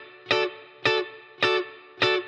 DD_TeleChop_105-Dmaj.wav